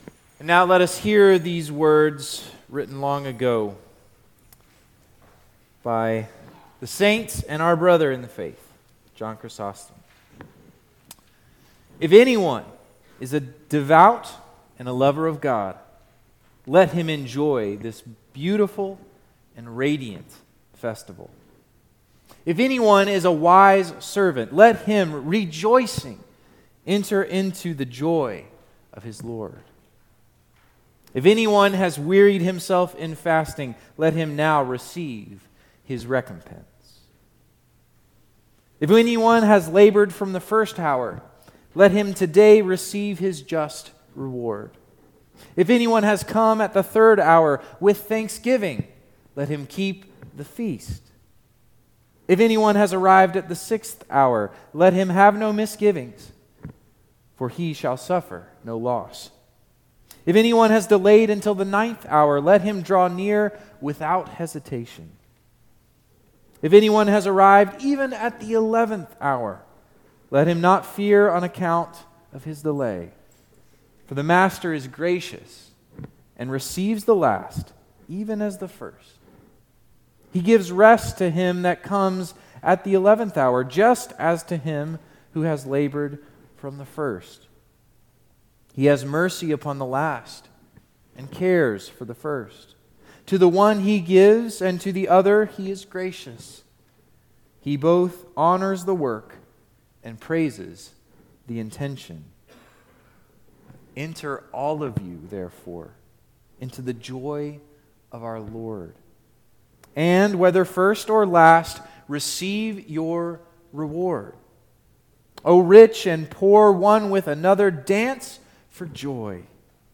Easter Vigil 2023 - Apostles Anglican Church